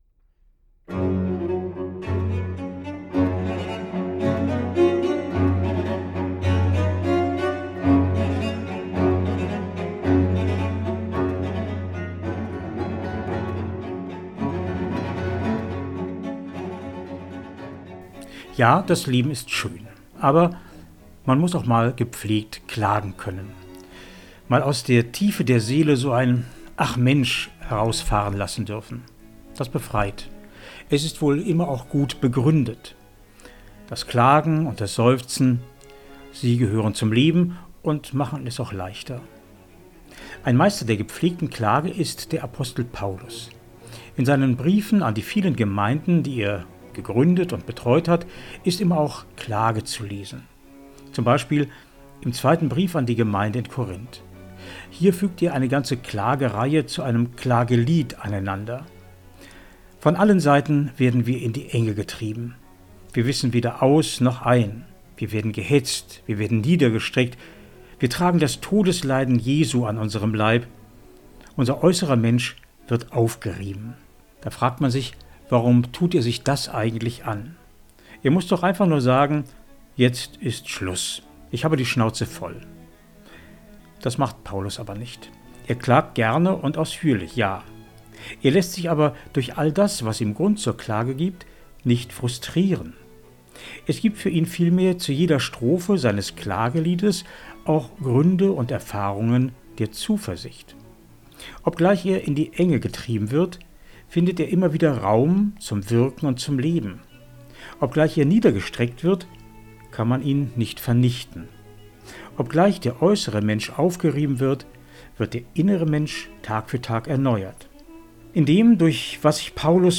Eine Meditation
MEDITATION